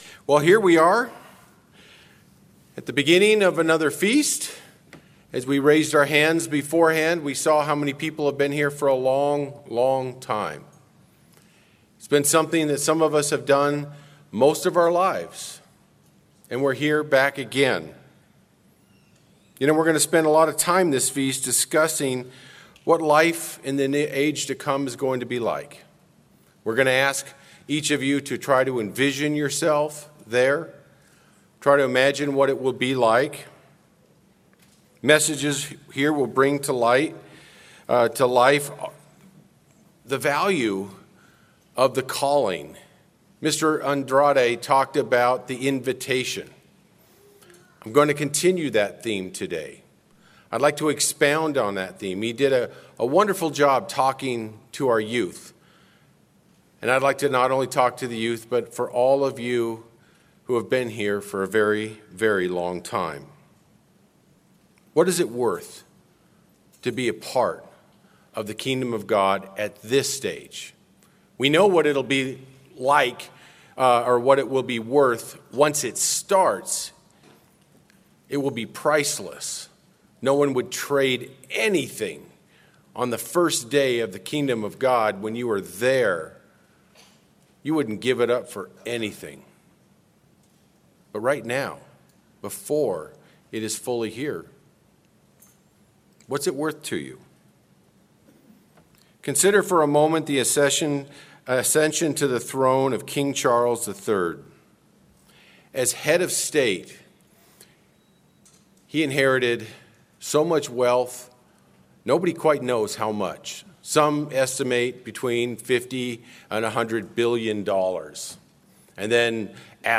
This sermon was given at the Bend-Redmond, Oregon 2022 Feast site.